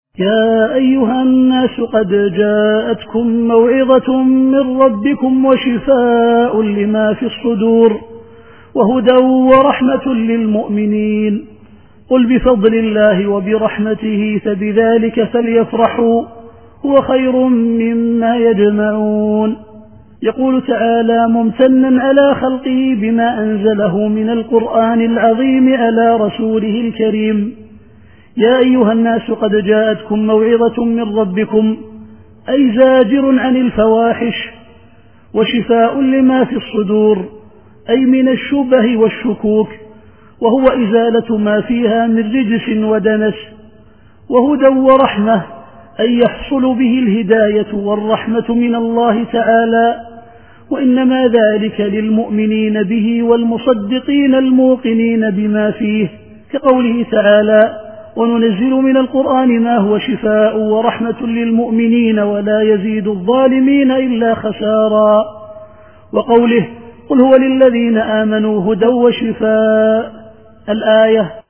التفسير الصوتي [يونس / 57]